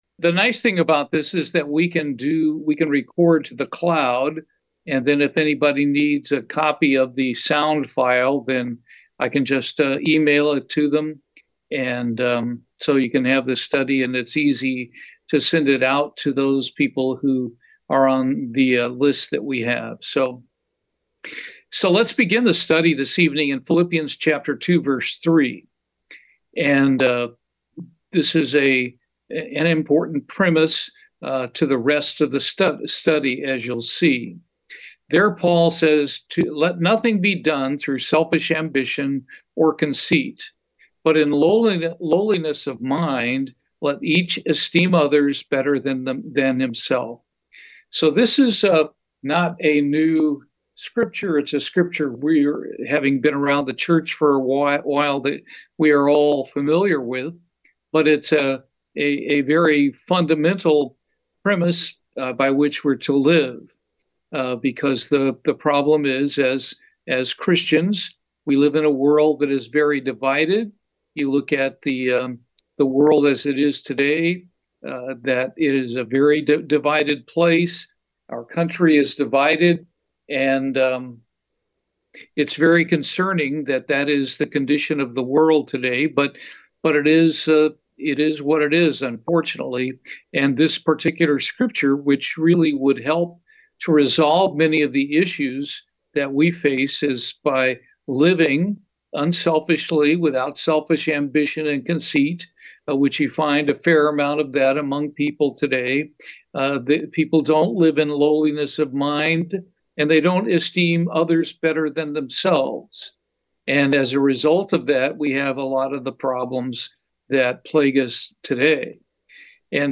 Bible Study - Jesus Christ, The Supreme Example